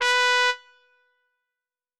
doot7.wav